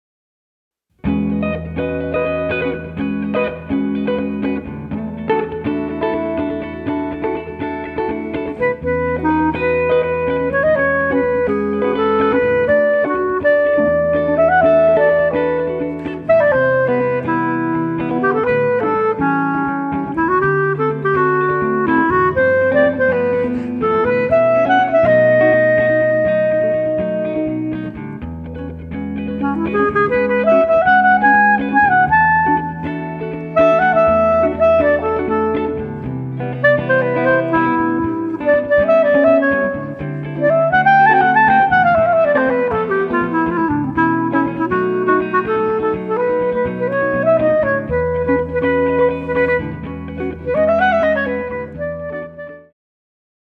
clarinet solo over the energetic rhythmic strumming